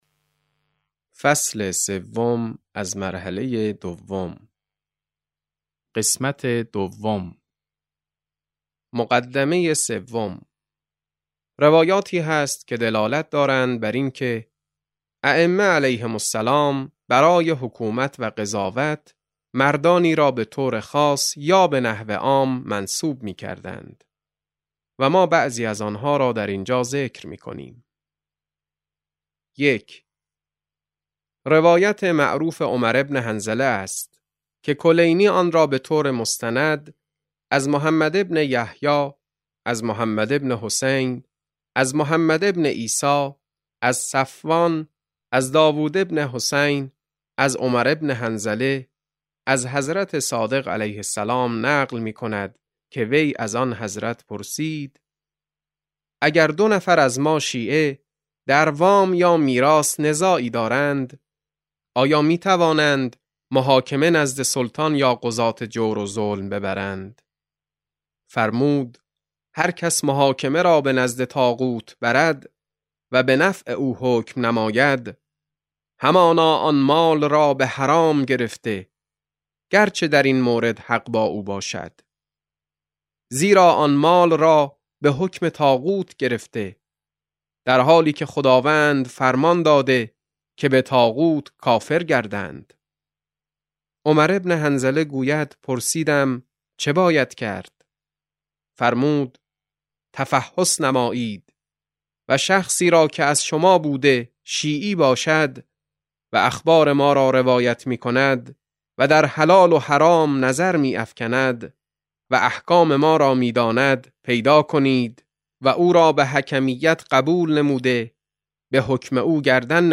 کتاب صوتی رساله بدیعه ( 12 تعداد فایل ها ) | علامه طهرانی | مکتب وحی